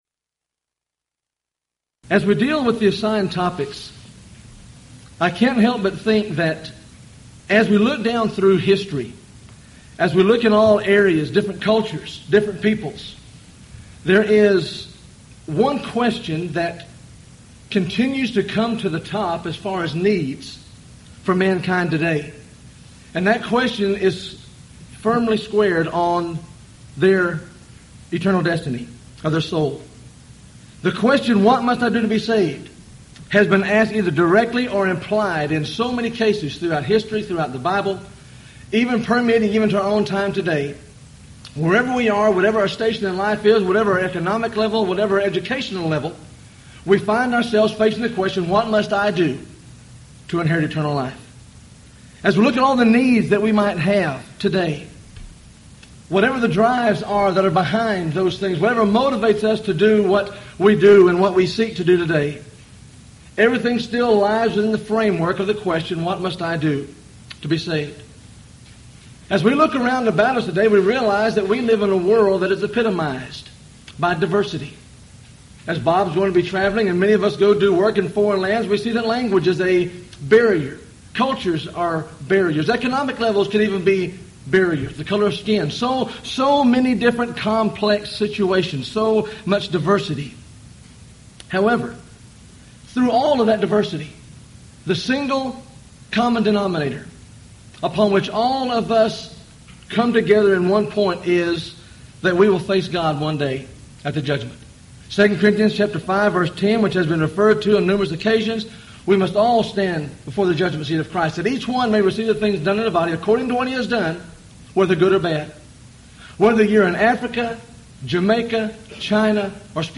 Event: 1998 Houston College of the Bible Lectures
lecture